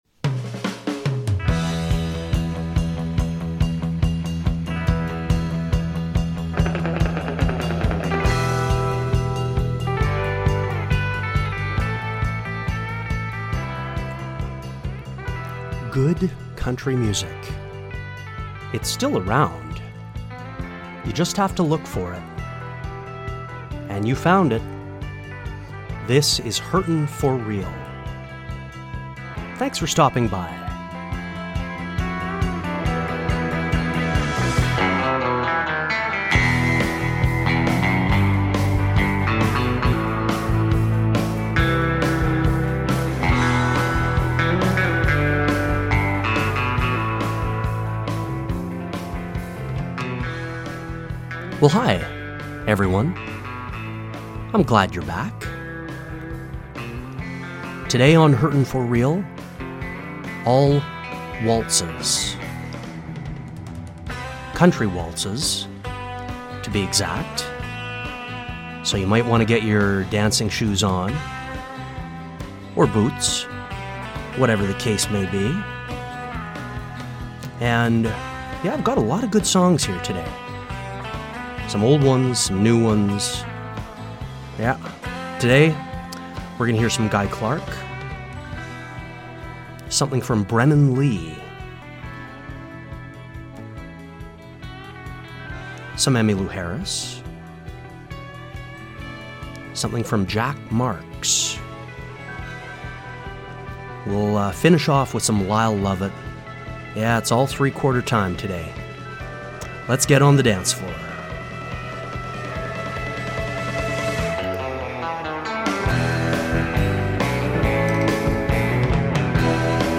Hurtin For Real – Show 786 – Country Waltzes